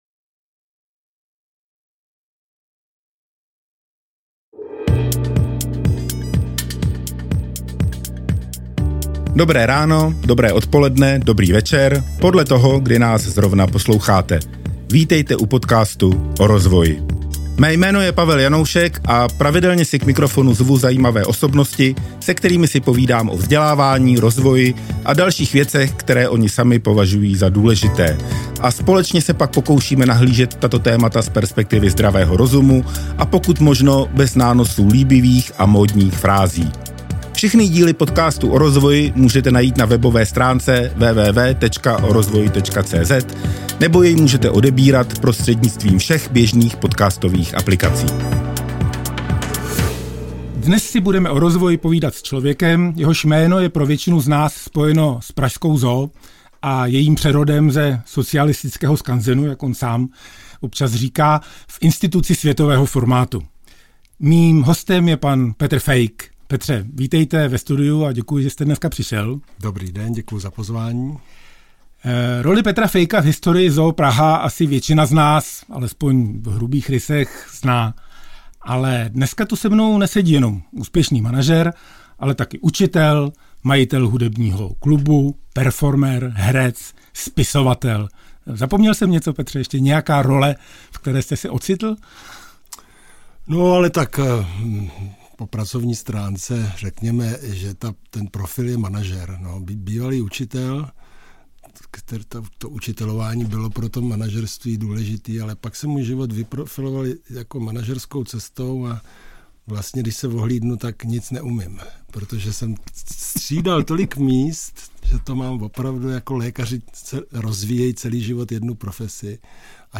V tomto otevřeném rozhovoru